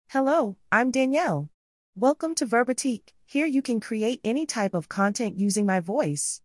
FemaleUS English
DanielleFemale US English AI voice
Danielle is a female AI voice for US English.
Voice sample
Listen to Danielle's female US English voice.
Danielle delivers clear pronunciation with authentic US English intonation, making your content sound professionally produced.